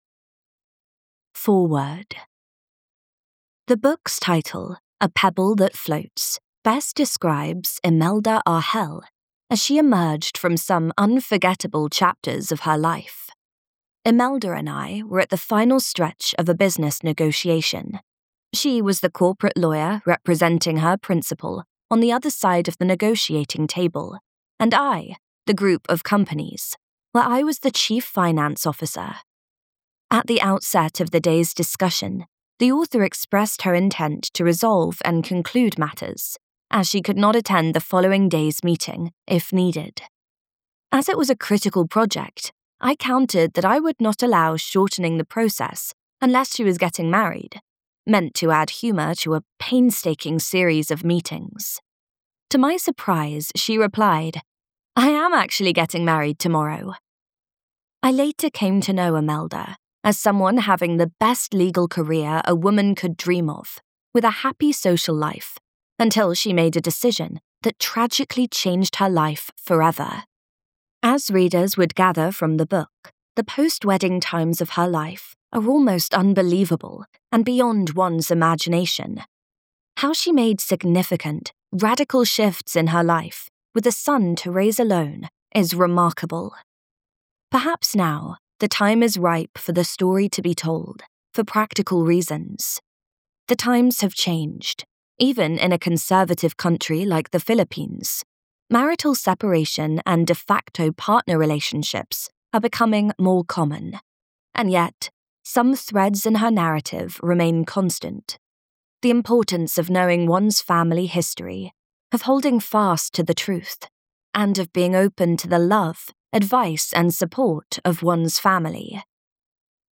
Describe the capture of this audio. Now brought to life in a stunning professional narration.